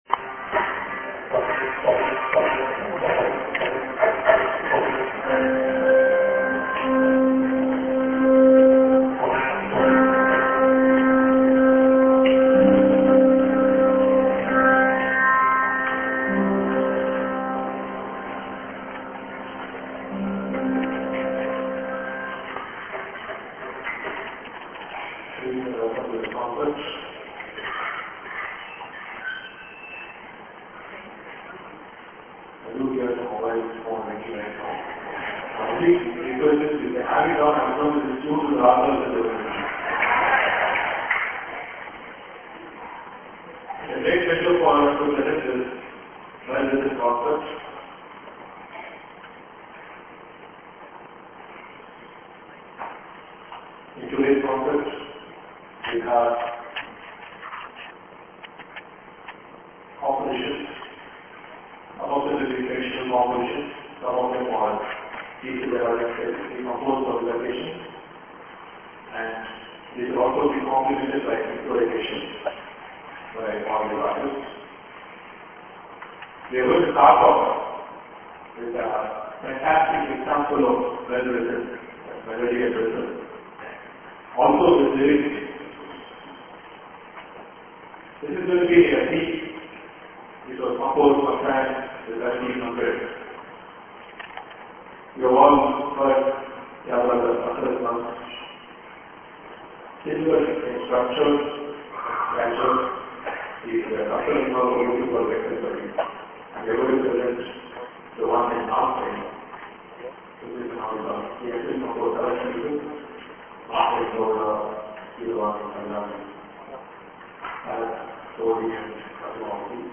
Special Concert
Venue: Satguru Gnanananda Hall , Chennai
Special Mel-Rhythm Concert: Sangeet Samrat Chitravina N. Ravikiran (Chitravina) Padmabhushan Sangita Kalanidhi Umayalpuram K. Sivaraman (Mridangam)
Bass Guitar & Strings
Hinudustani Flute
Carnatic Violin
Tabla
Ghatam